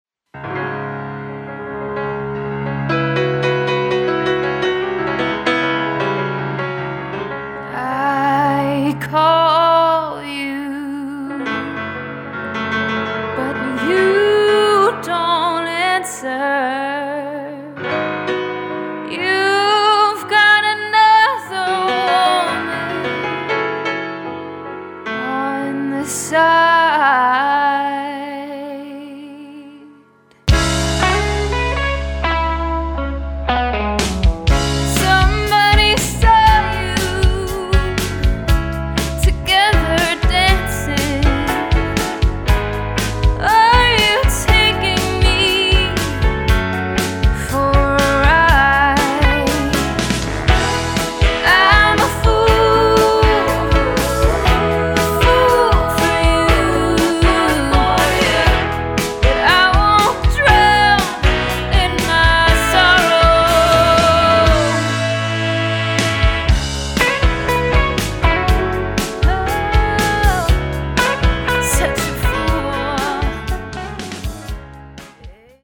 a wonderful young singer
bluesy